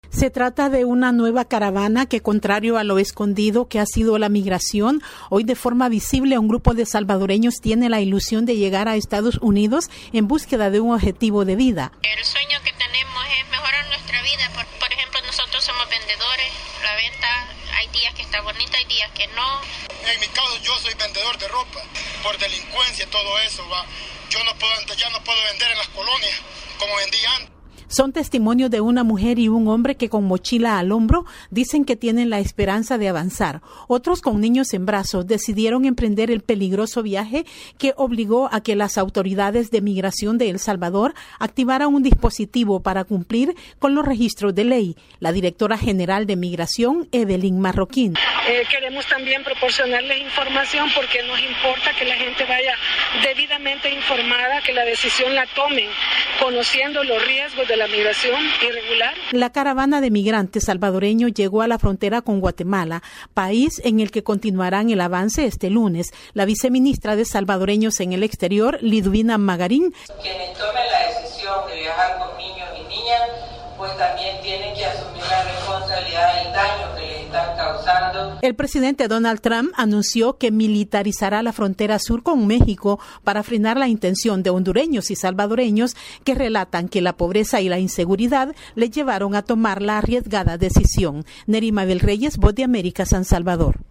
VOA: Informe desde el Salvador